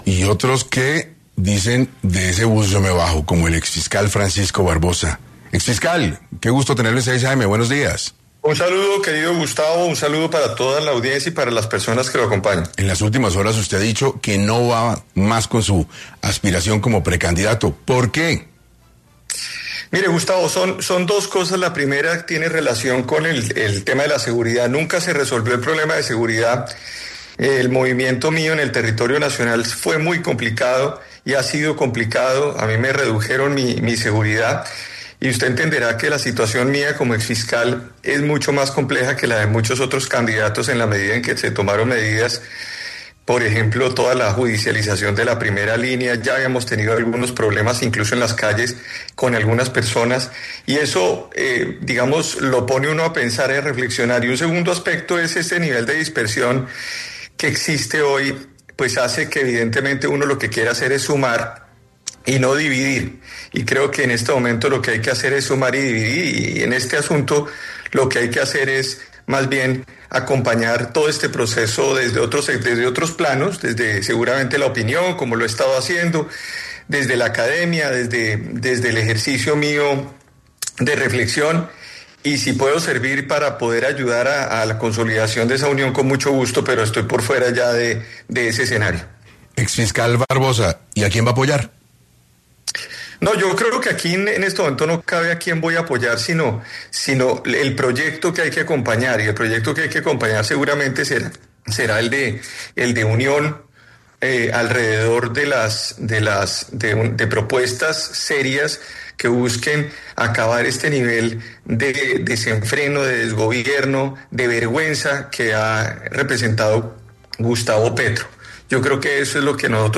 El exfiscal general, Francisco Barbosa, estuvo en 6AM para abordar las principales razones, relacionadas con su declinación a la candidatura presidencial.